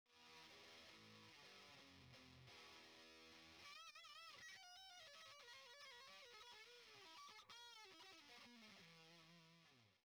クリーンアンプ接続時にもまさに「クアトロバルボーレ」の名の通り４本の真空管の如く大型ハイゲインアンプ張りの重低音と単に重いだけではない、分離の良い生々しいサウンドを放出。